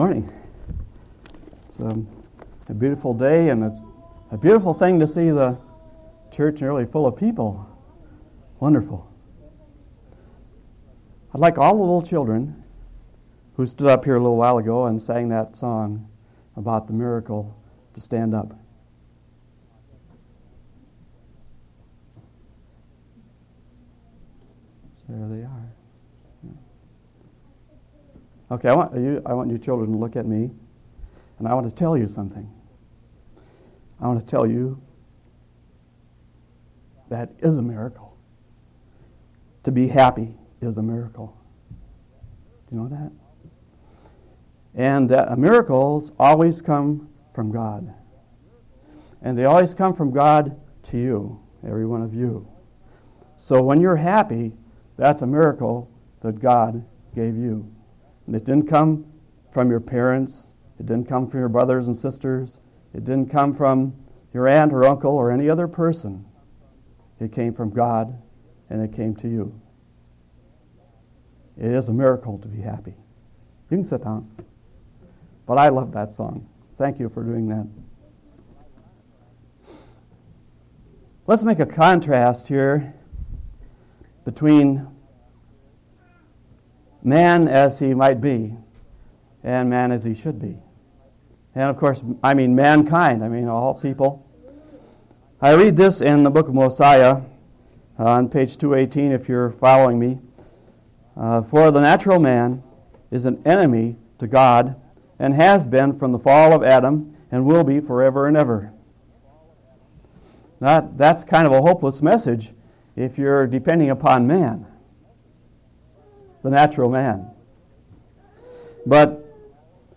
7/17/1994 Location: Collins Local Event